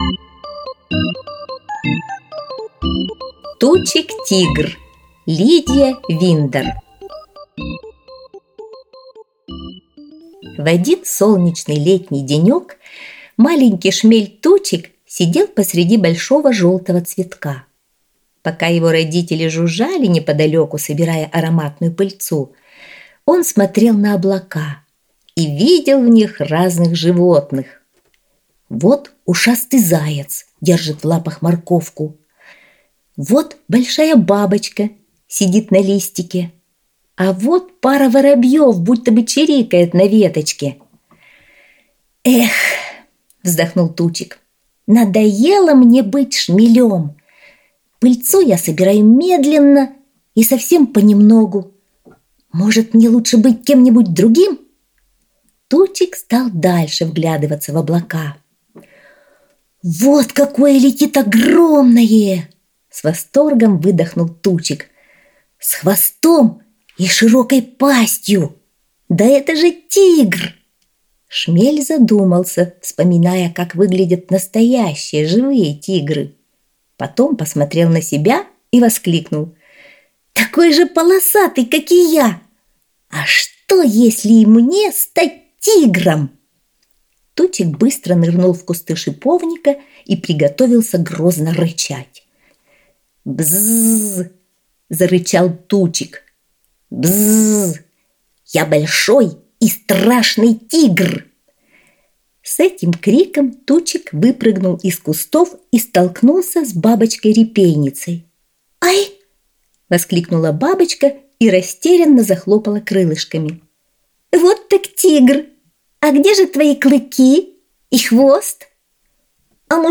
Аудиосказка «Тучик-тигр»